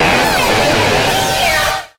Grito de Dusknoir.ogg
Grito_de_Dusknoir.ogg.mp3